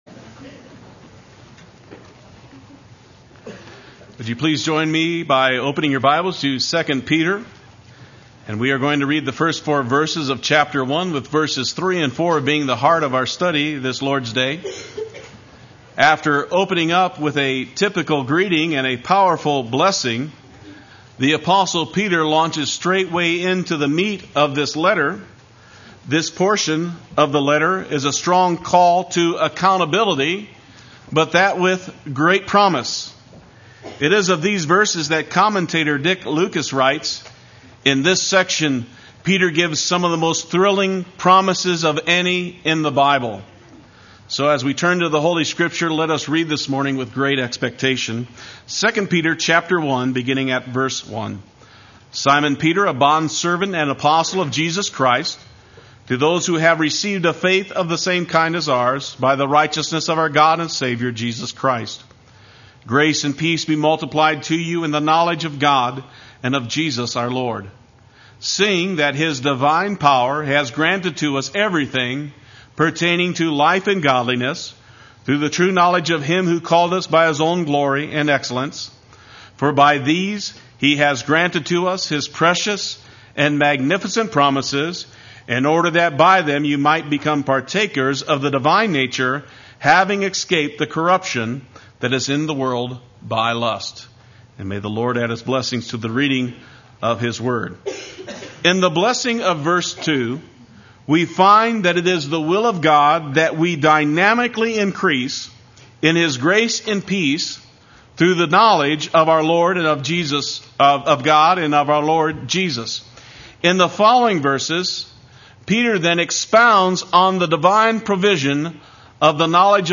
Play Sermon Get HCF Teaching Automatically.
Through the True Knowledge Sunday Worship